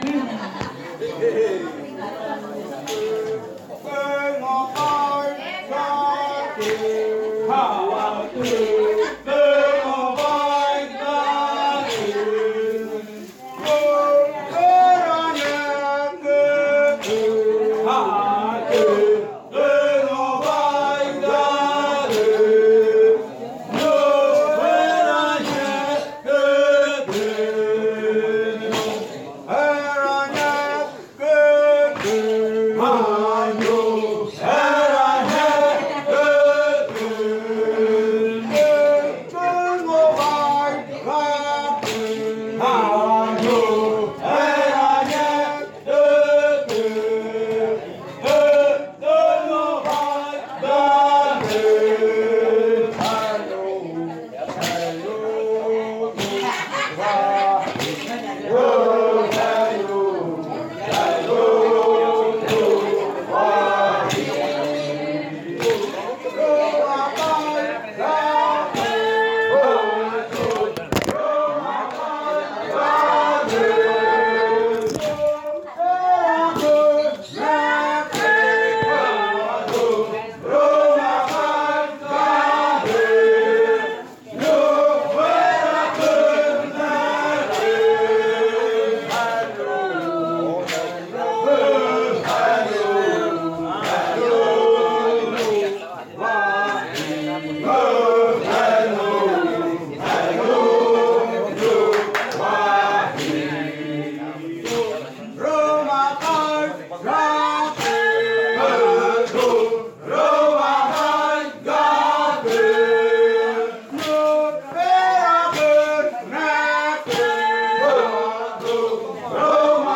Canto de la variante jaiokɨ
Leticia, Amazonas
con el grupo de cantores bailando en Nokaido.
with the group of singers dancing in Nokaido. This song is part of the collection of songs from the yuakɨ murui-muina ritual (fruit ritual) of the Murui people, a collection that was compiled by the Kaɨ Komuiya Uai Dance Group with support from UNAL, Amazonia campus.